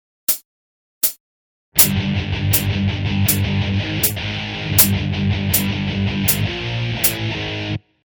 Voicing: Guitar Method